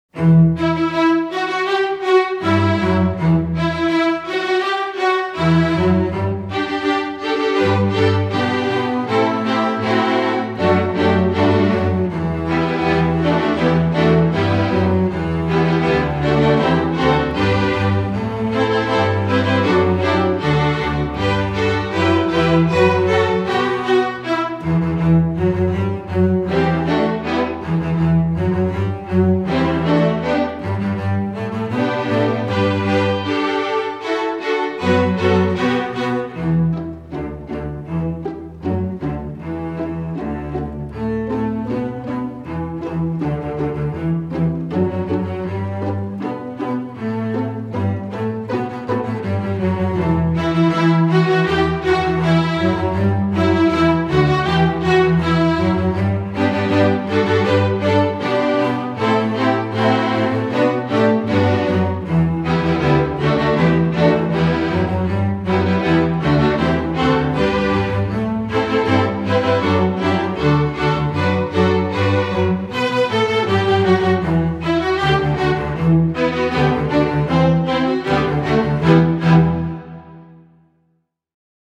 String Orchestra